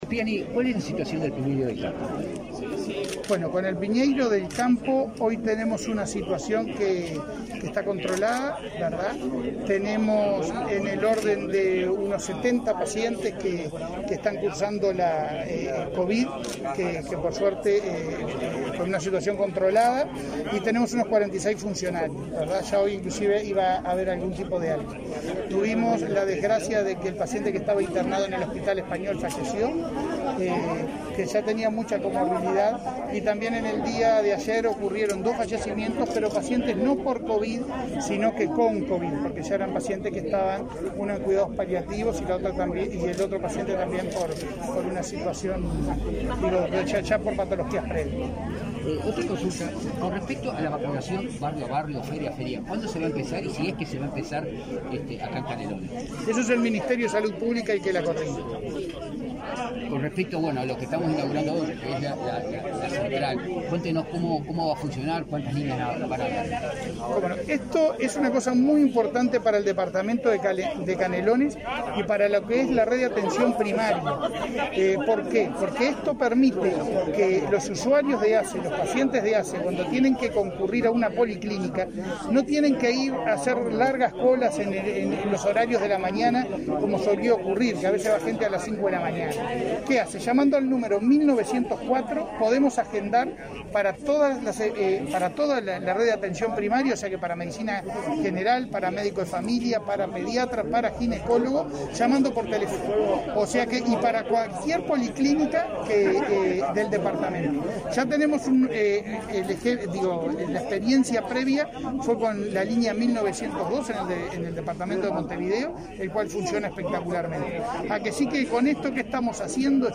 Declaraciones a la prensa del presidente de ASSE, Leonardo Cipriani
Declaraciones a la prensa del presidente de ASSE, Leonardo Cipriani 01/02/2022 Compartir Facebook X Copiar enlace WhatsApp LinkedIn Tras la inauguración del servicio telefónico de la Red de Atención Primaria de Canelones, este 1 de febrero, el presidente de la Administración de los Servicios de Salud del Estado (ASSE), Leonardo Cipriani, efectuó declaraciones a la prensa.